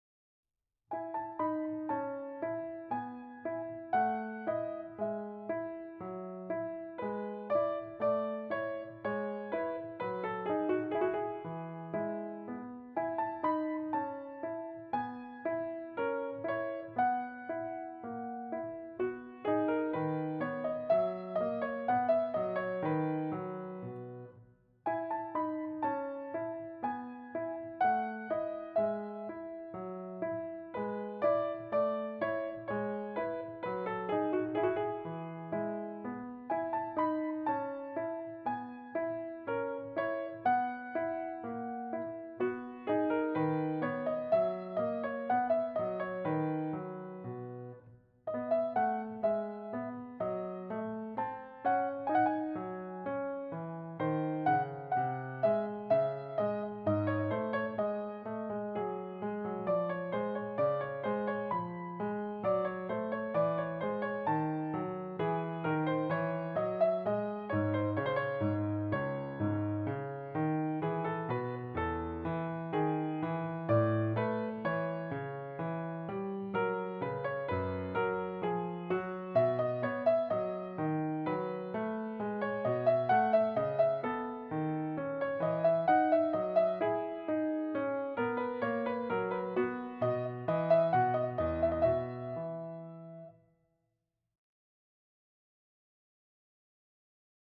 Wenn Sie meine Amateur-Aufnahmen hören möchten, dann heiße ich Sie herzlich Willkommen.
Hören Sie mich am Klavier!